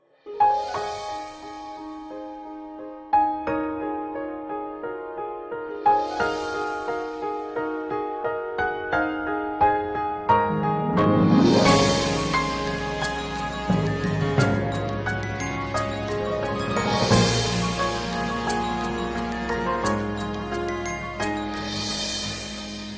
• Качество: 128, Stereo
сказочная мелодия